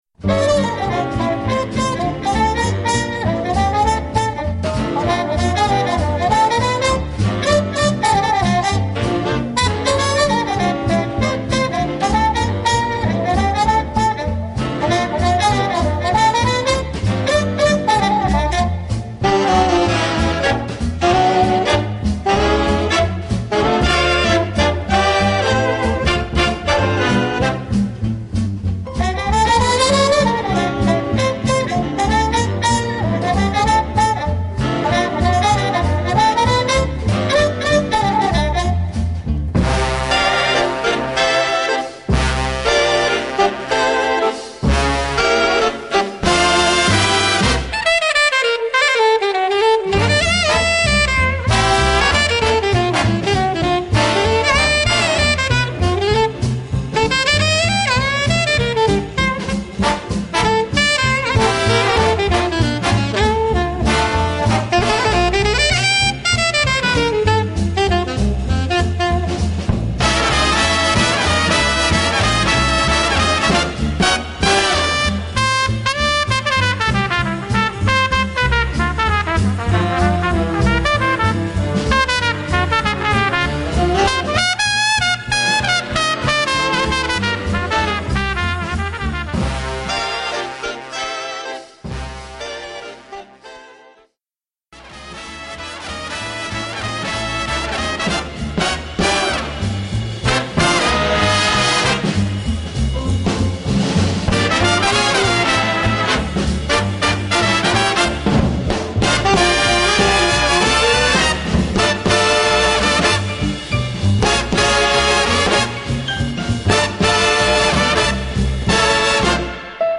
Bright Swing
Voicing: Jazz Ensemble